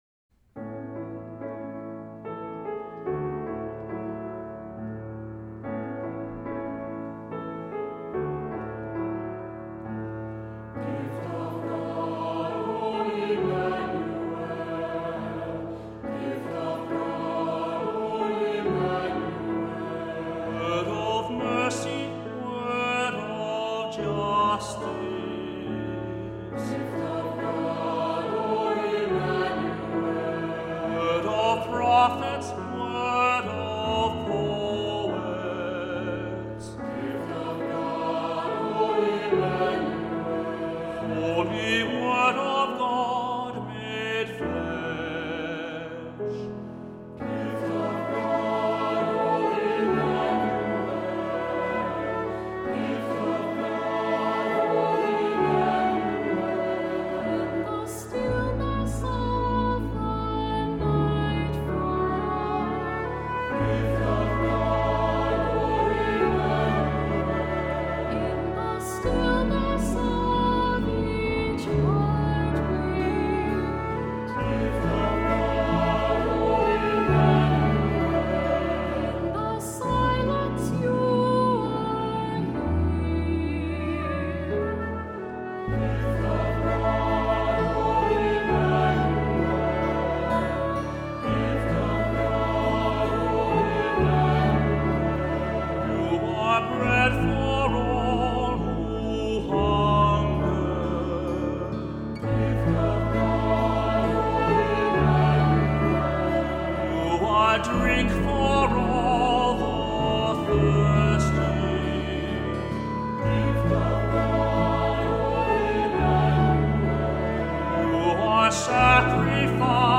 Accompaniment:      Keyboard, C Instrument
Music Category:      Choral